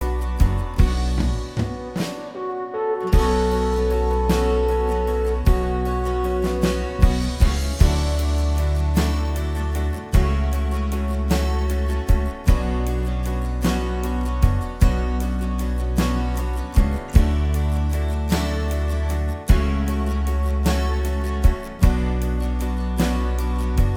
Minus Electric Guitar Pop (1980s) 2:51 Buy £1.50